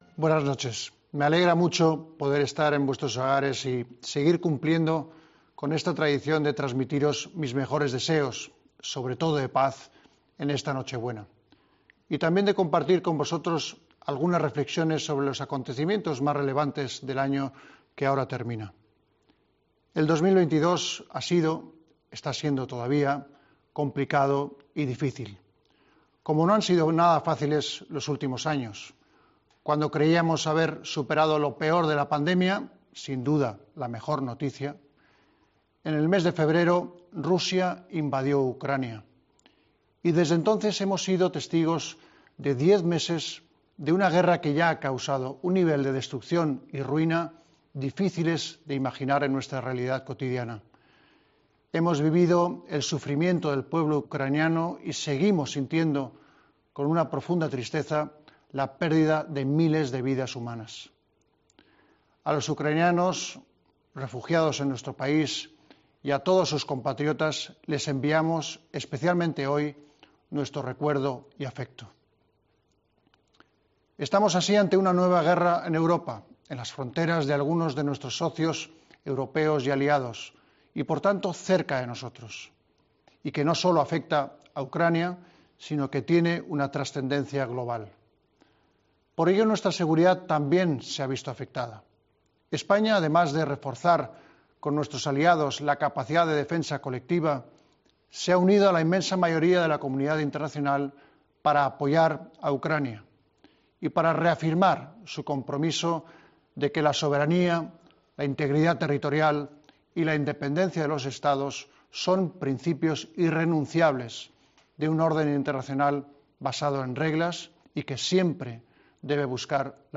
Vuelve a escuchar el mensaje de Navidad de Su Majestad el Rey Felipe VI